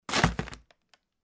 trashplastic5.mp3